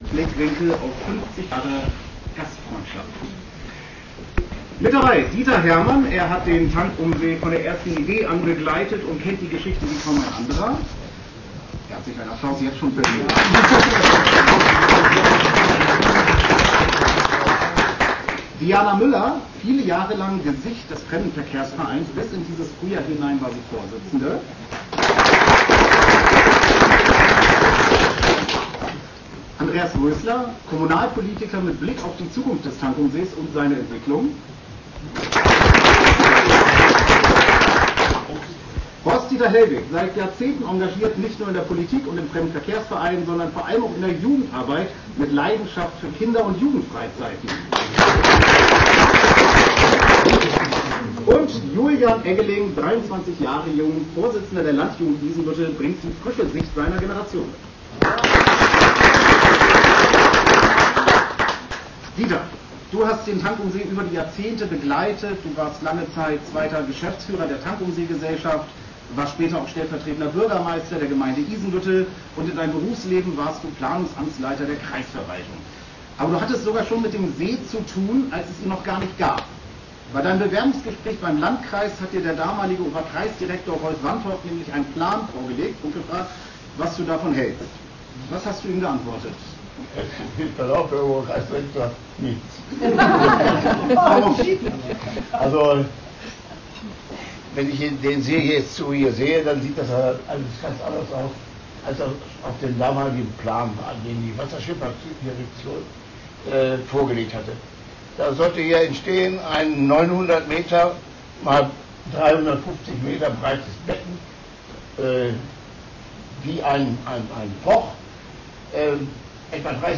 Am 12.9.2025 feierte der Fremdenverkehrsverein Tankumsee Samtgemeinde Isenbüttel e. V. in einer kleinen Feierstunde im Seehotel sein 50jähriges Bestehen. 50 Jahre Fremdenverkehr in unserer Samtgemeinde.